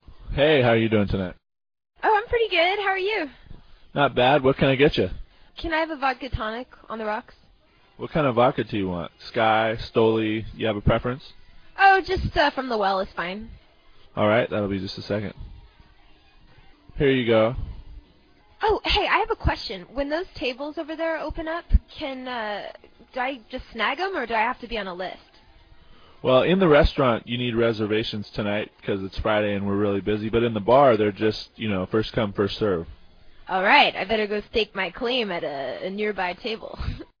Check out the dialogue below for an example of how to speak to someone who's serving you.